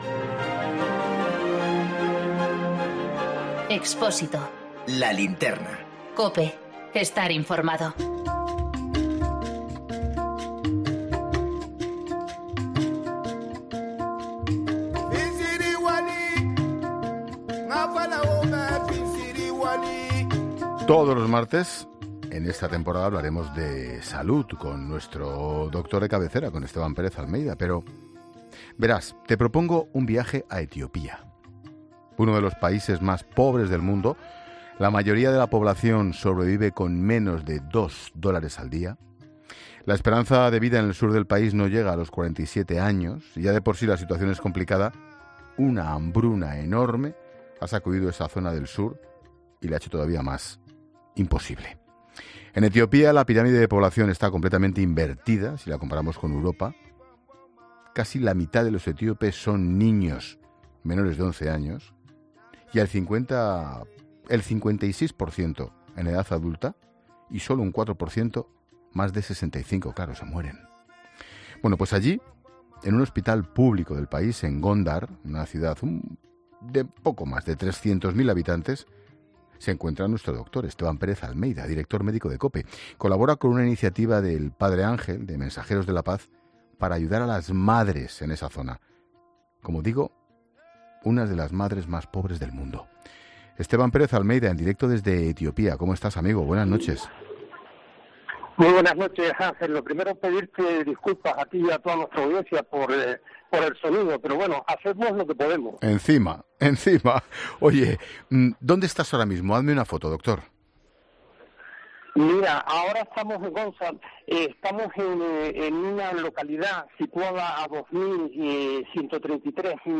nos recibe en un hospital de Gondar